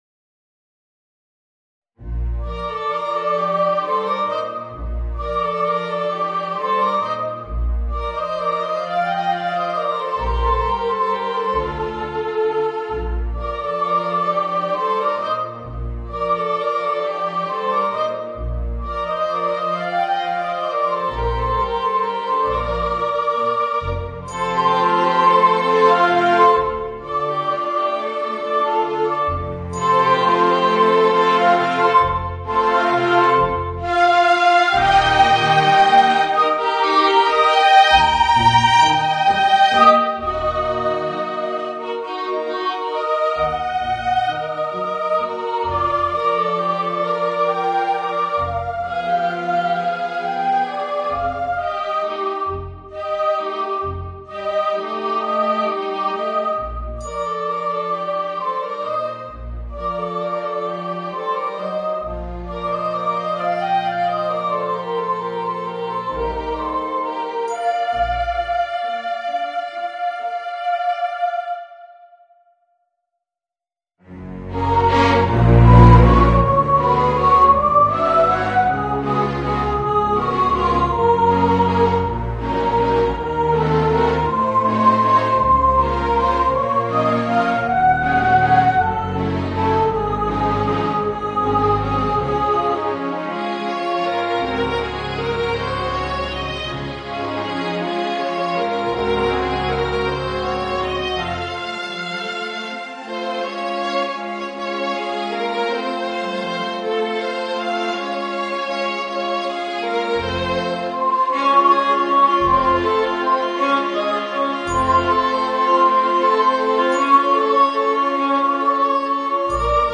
Voicing: Soprano, Violin and Orchestra